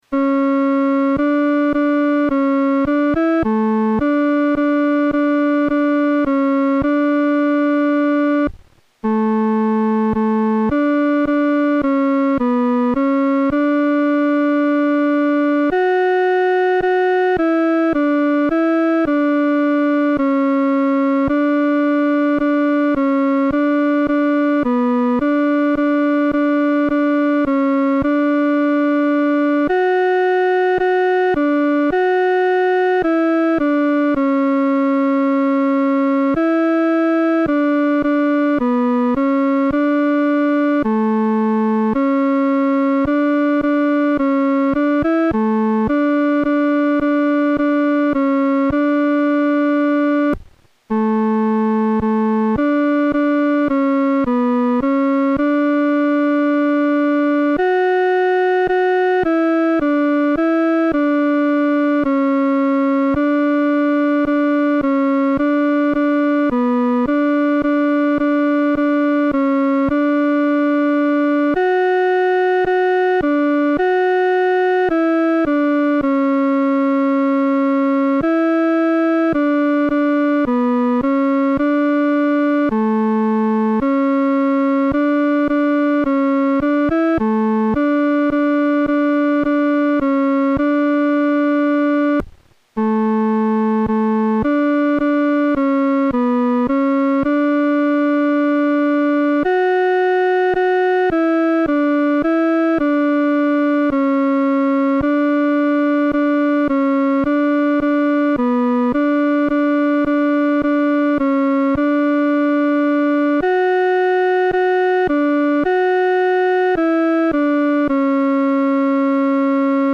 合唱
本首圣诗由网上圣诗班 (石家庄二组）录制
这是一首比较雄壮的圣诗，速度以中速为宜。